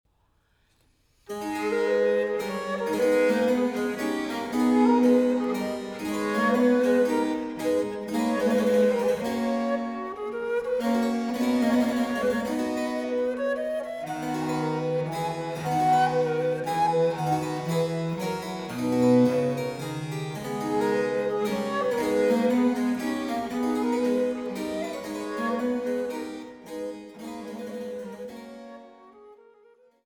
Allegro